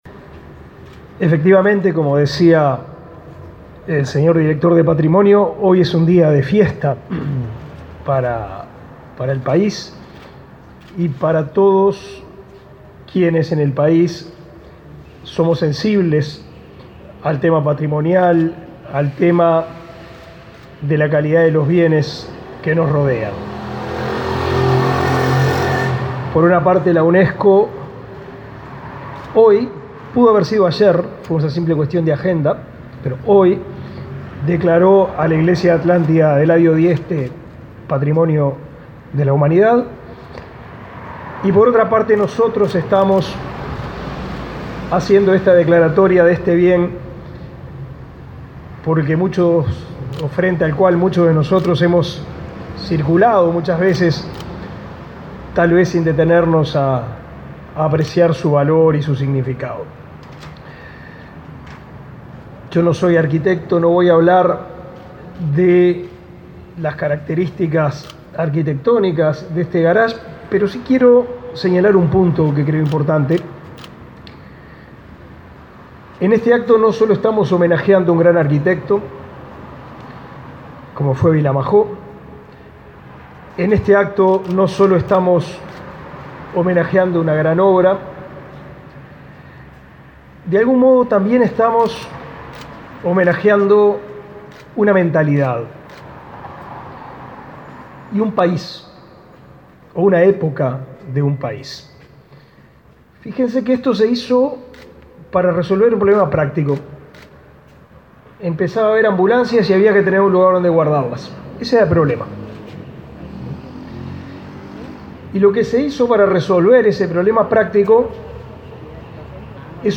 Declaraciones del ministro de Educación y Cultura y el director de la Comisión de Patrimonio
El ministro de Educación y Cultura, Pablo da Silveira, y el director de la Comisión del Patrimonio Cultural de la Nación, William Rey, participaron,